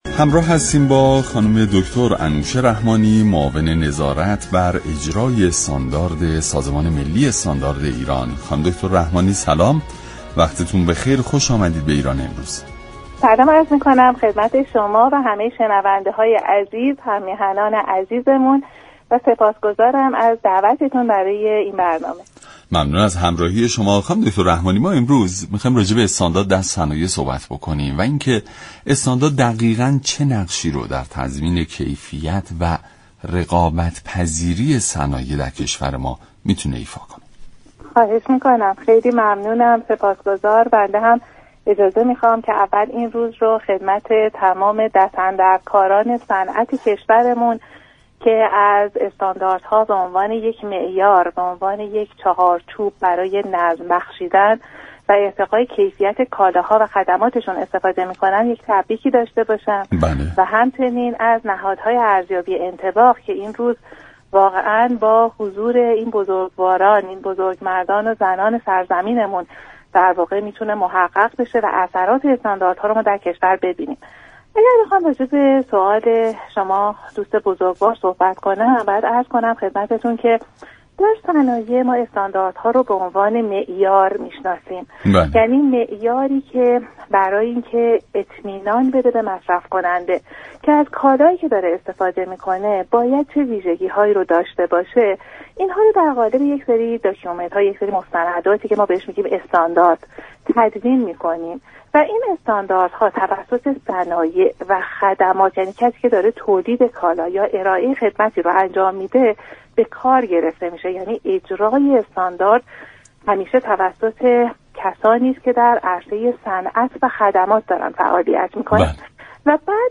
معاون نظارت بر اجرای استاندارد سازمان ملی در ایران امروز گفت: اجراكننده استاندارد كسانی هستند كه در عرصه صنعت و خدمات فعالیت می‌كنند.